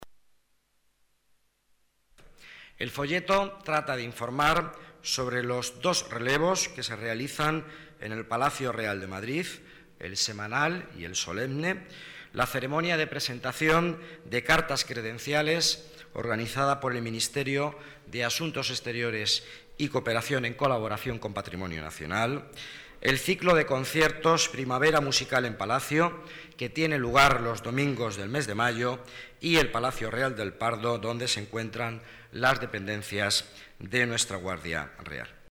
Nueva ventana:Declaraciones del delegado de Economía y Empleo, Miguel Ángel Villanueva